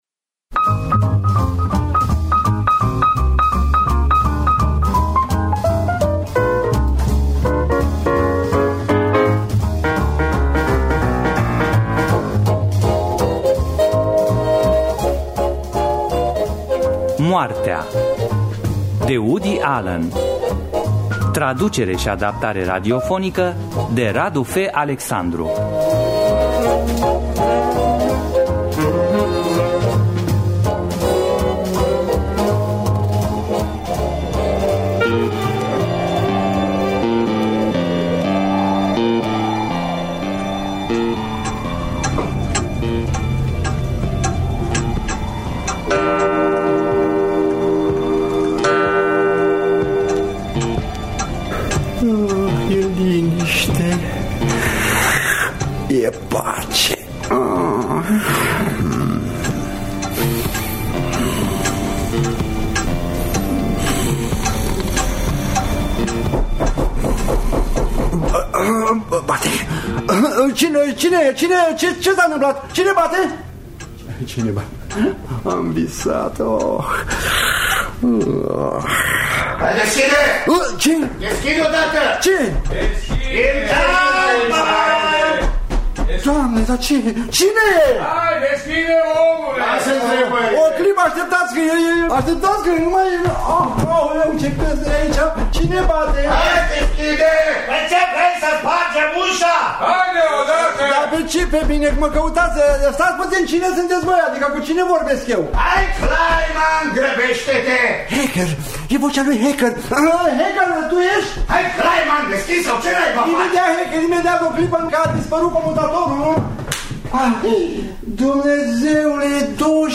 Traducerea şi adaptarea radiofonică de Radu F. Alexandru.